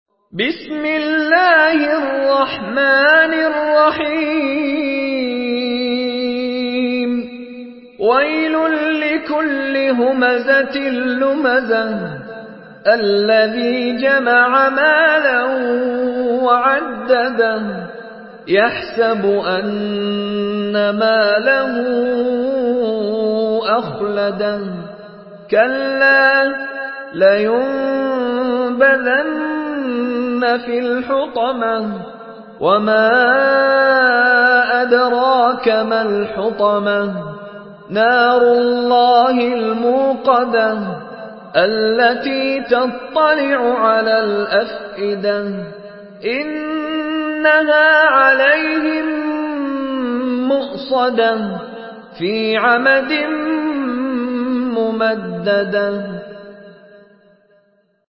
سورة الهمزة MP3 بصوت مشاري راشد العفاسي برواية حفص
مرتل